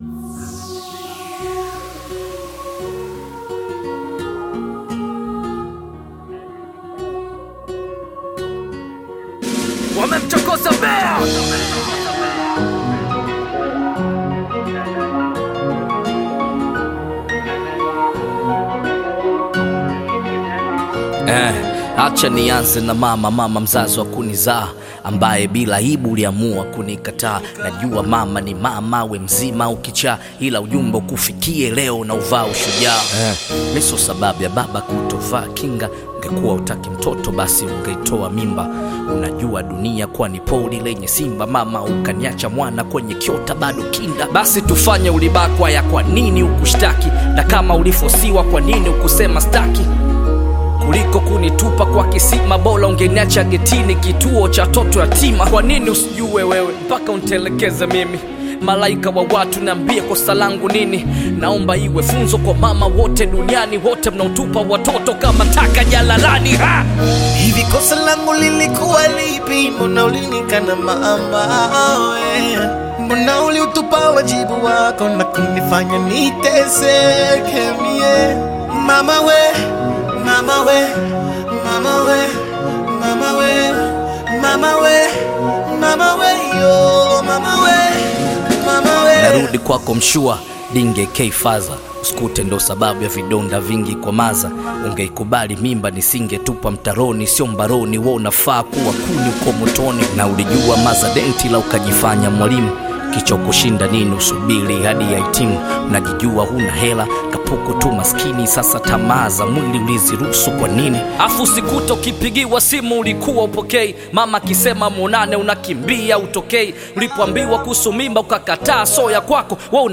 Bongo Flava music track
catchy new song